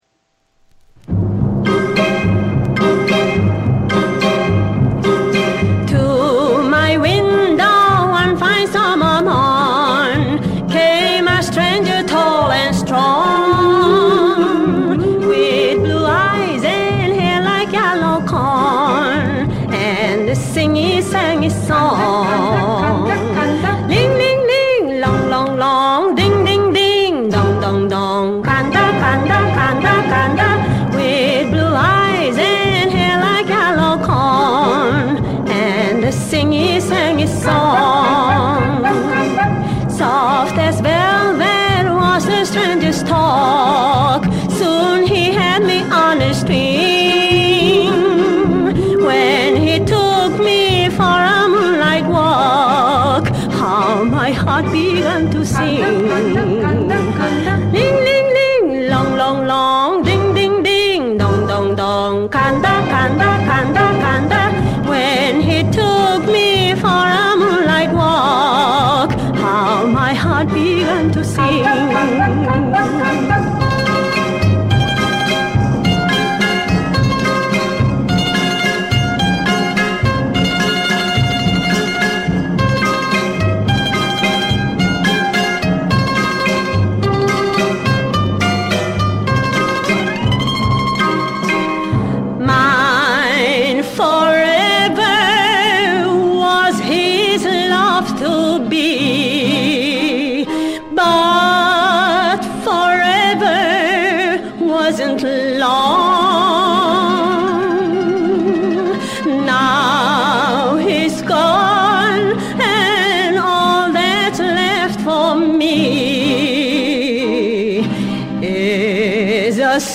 ♠그때 그 시절 옛 가요/★50~60년(측음기)